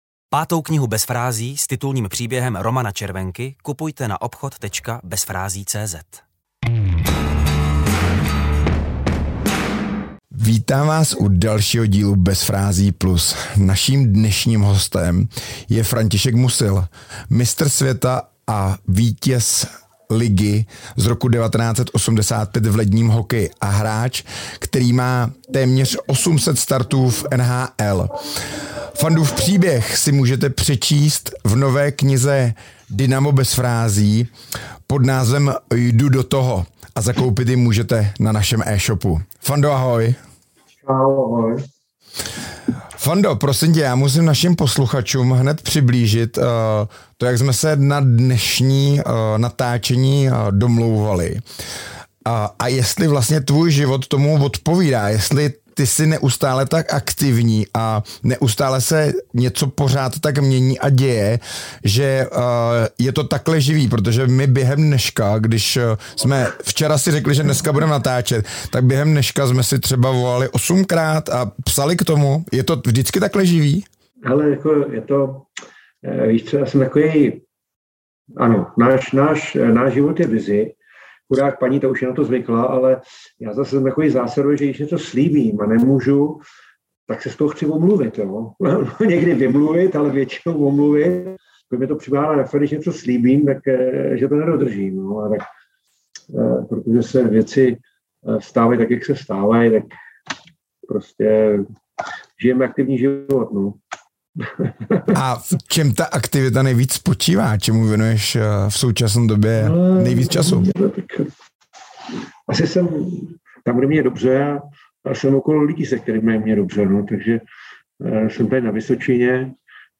Najdete v ní i vyprávění Františka Musila , mistra světa z roku 1985 s téměř osmi sty zápasy v NHL a nejnovějšího hosta Bez frází+.